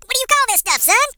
share/hedgewars/Data/Sounds/voices/HillBilly/Cutitout.ogg
Cutitout.ogg